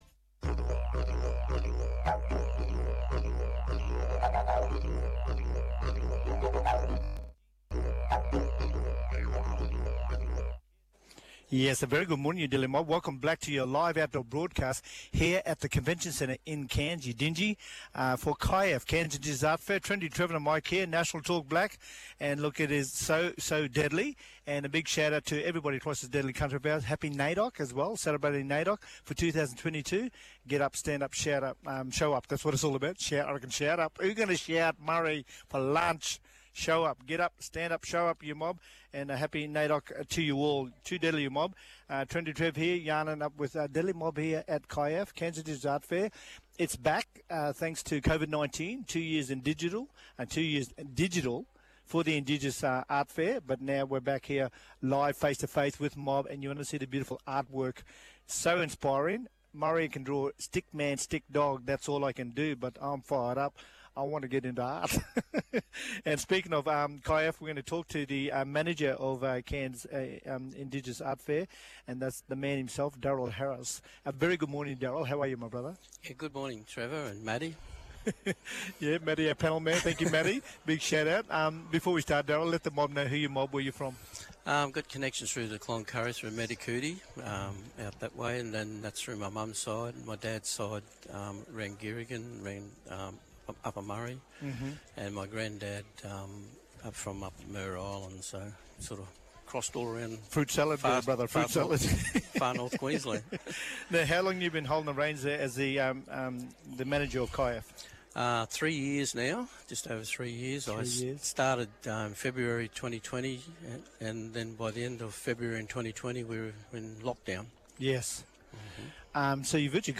Tune in to listen back on the guests interviewed from Cairns Indigenous Art Fair Outdoor Broadcast!